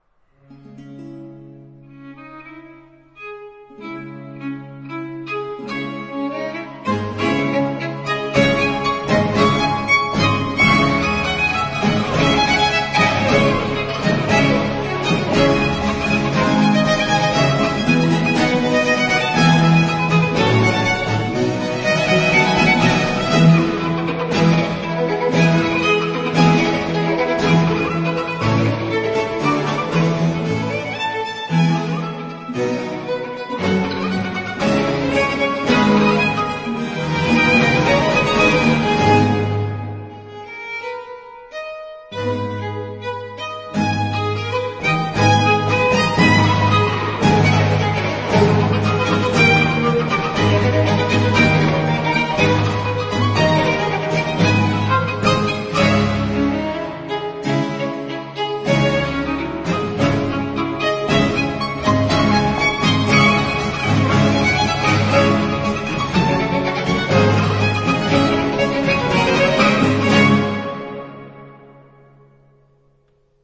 其聲響是豐富的。
試聽二則是兩把小提琴與連續低音的三重奏，
這些曲子好聽、美麗豐富。